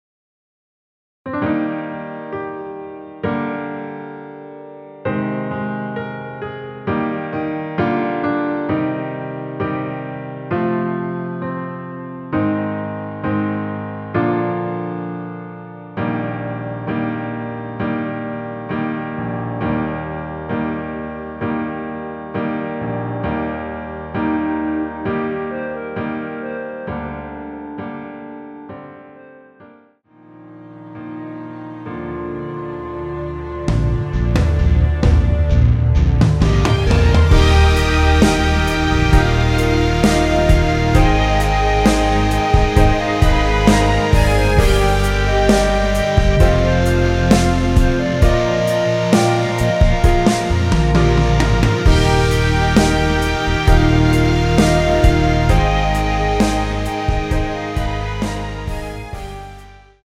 원키에서(+5)올린 멜로디 포함된 MR입니다.
Bb
앞부분30초, 뒷부분30초씩 편집해서 올려 드리고 있습니다.
중간에 음이 끈어지고 다시 나오는 이유는